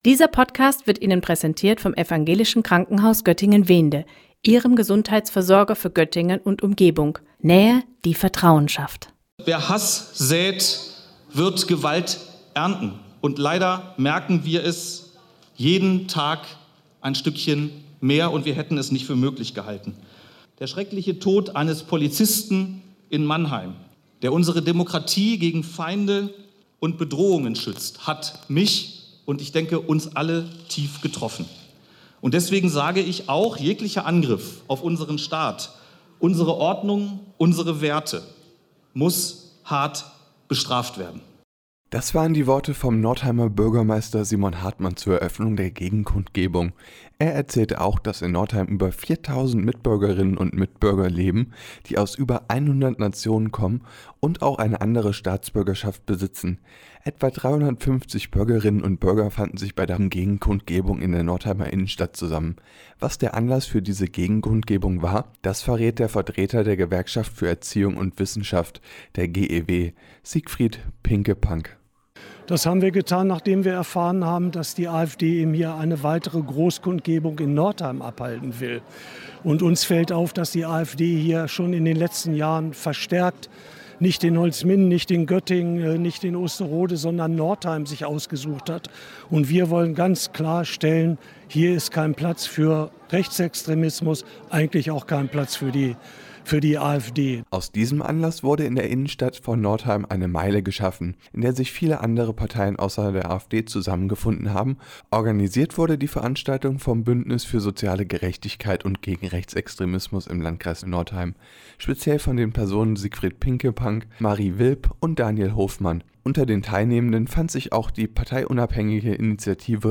Eine Menschenkette gegen die AfD bildeten Freitagabend viele Menschen in Northeim.